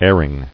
[air·ing]